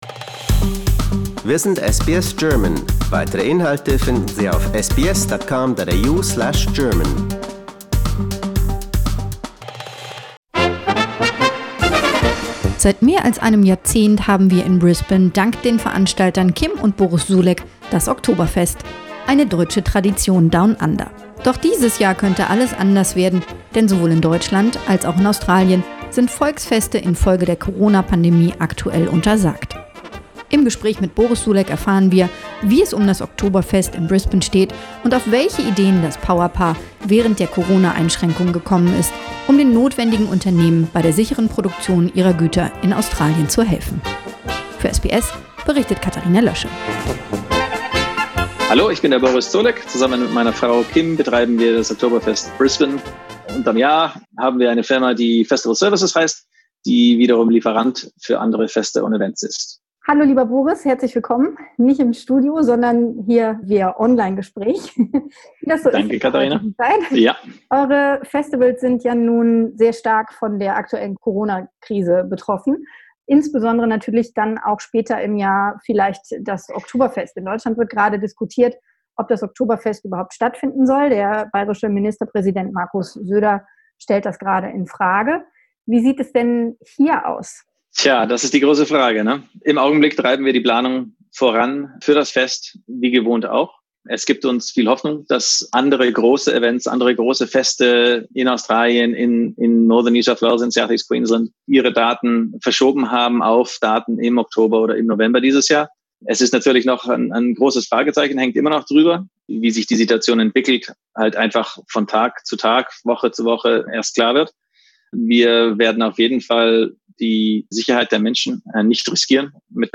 But this year everything could be different, because in both Germany and Australia, folk festivals are currently prohibited due to the corona pandemic. In conversation